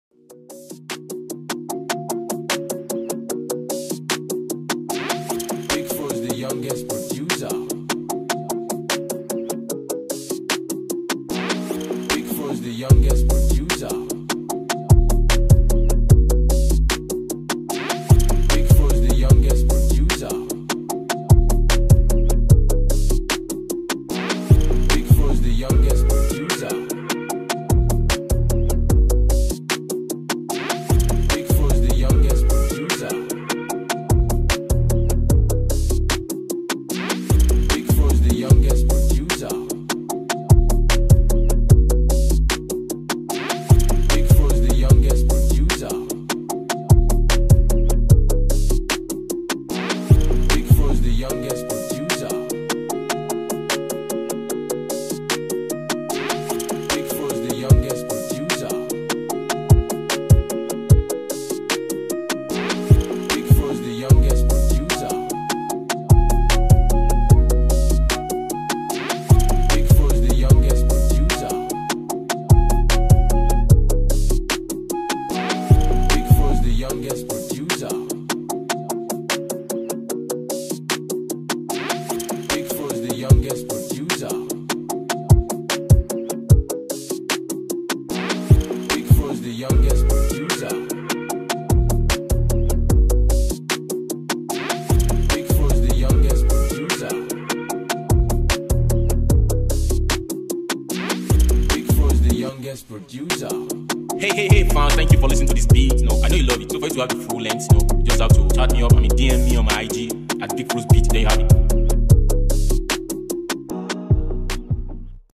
free hip hop pop instrumental